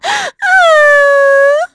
Estelle-Vox_Sad_b.wav